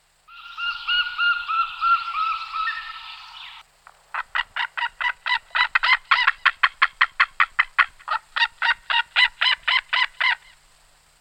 Pygargue à queue blanche, haliaeetus albicilla
pygargue.mp3